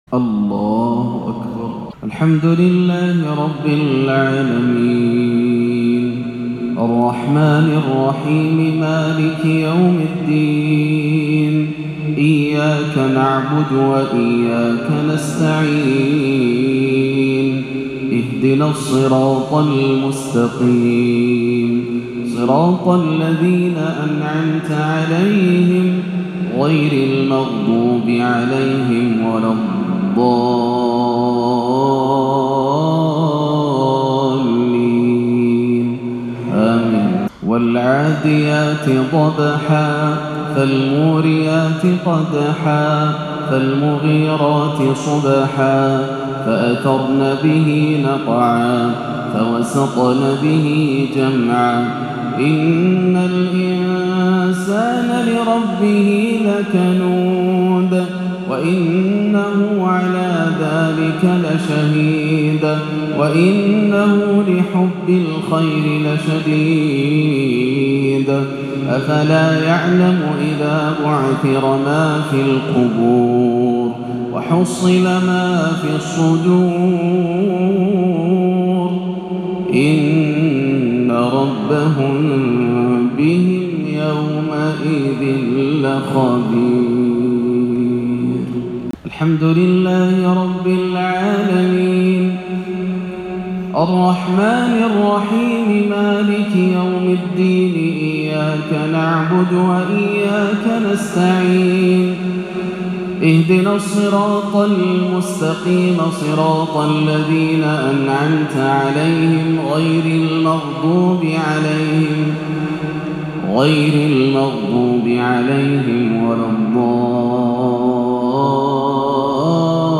ترتيل بديع لسورتي العاديات والتكاثر مغرب 8-3-1439 > عام 1439 > الفروض - تلاوات ياسر الدوسري